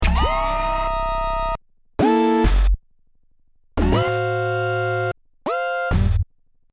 In Logic ProX, I created some “hopping up and hopping down” audio clips, which I could synchronize to  OnCollisionEnter and OnCollisionExit methods.
updown_indesign.mp3